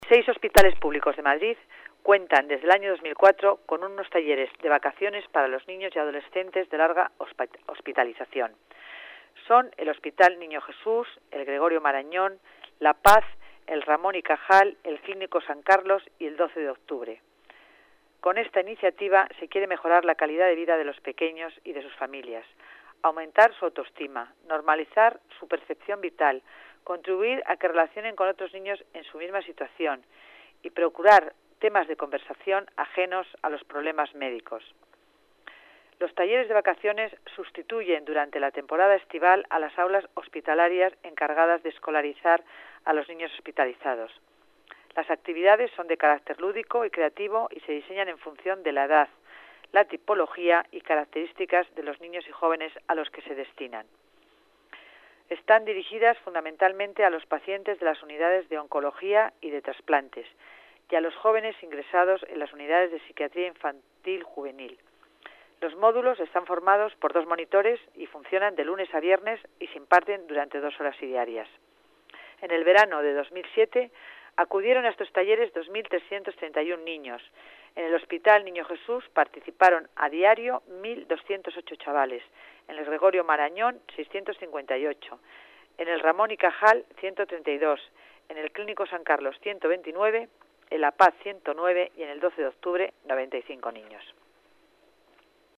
Nueva ventana:Declaraciones de la delegada de Familia y Servicios Sociales, Concepción Dancausa